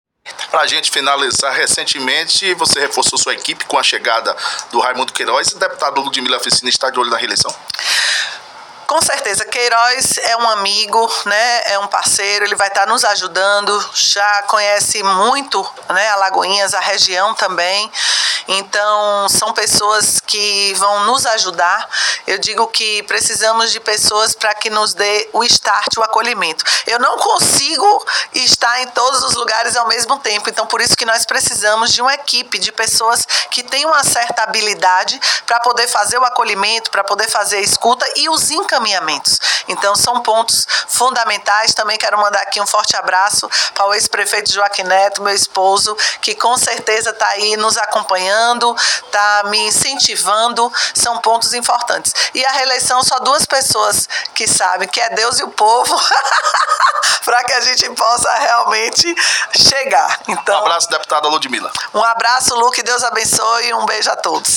Ouça na íntegra o pronunciamento da deputada Ludmilla Fiscina: